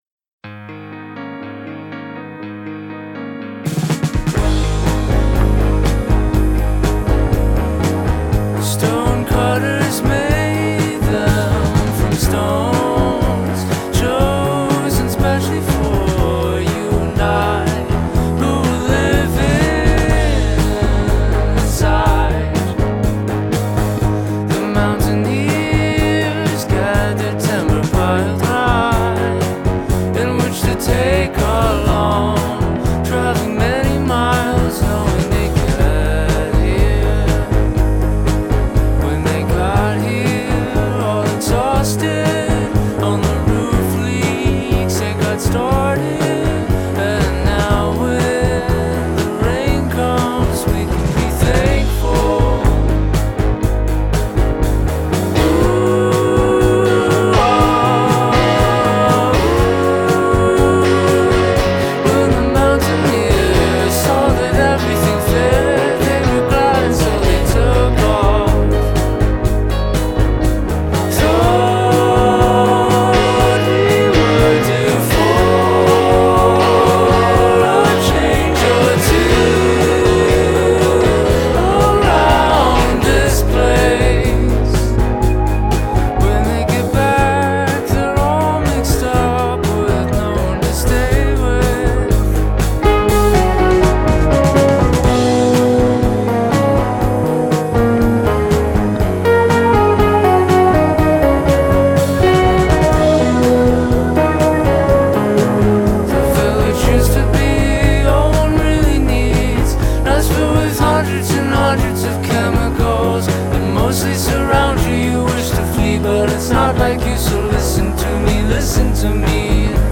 all fuzzy warmth and chugging chords